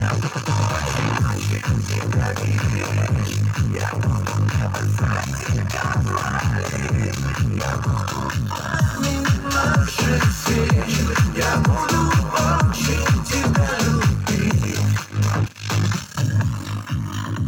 Один канал на минимум громкости уходит в перегруз и звук хрипит.